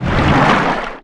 Sfx_creature_penguin_barrel_roll_01.ogg